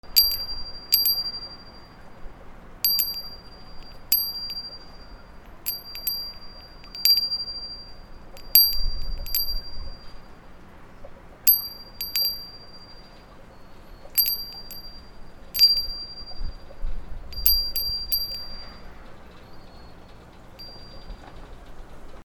/ G｜音を出すもの / G-01 機器_ベル・非常ベル
小さな鈴
チリンチリン